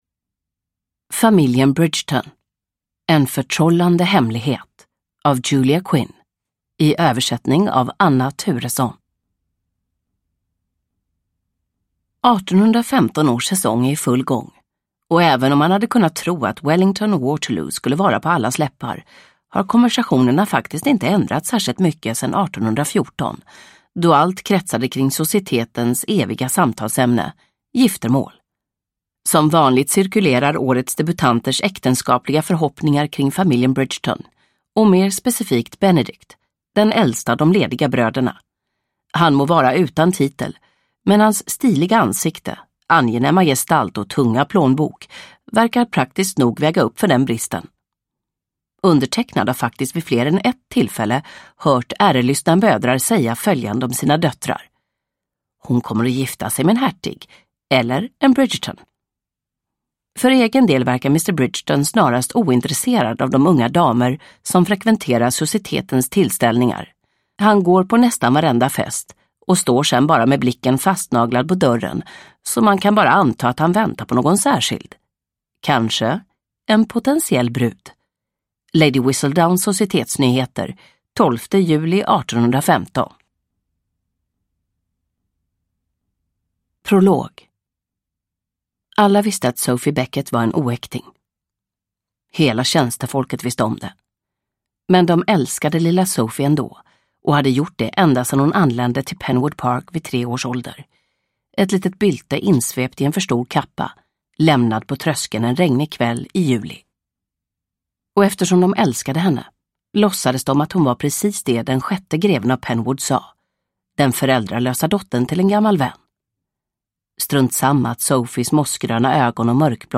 En förtrollande hemlighet – Ljudbok – Laddas ner